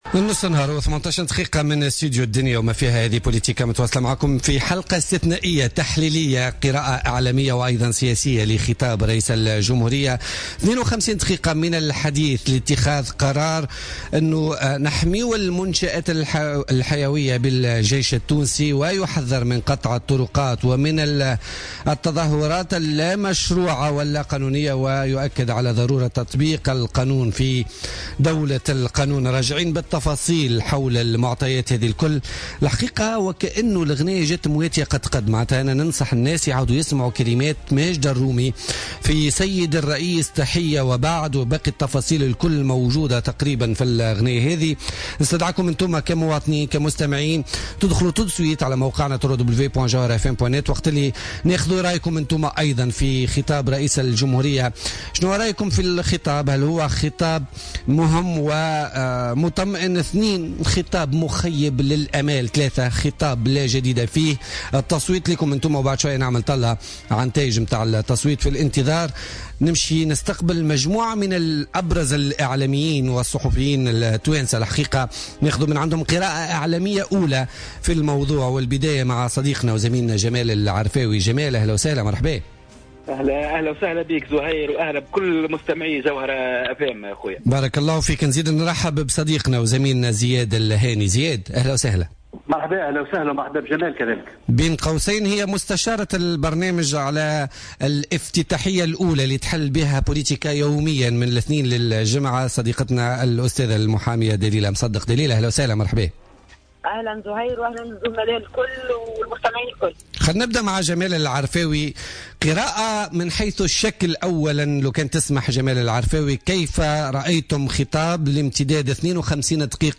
Lecture du discours de Béji Caid Essebsi